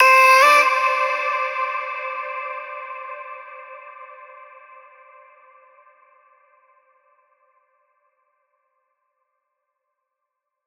VR_vox_hit_aaah_Dmin.wav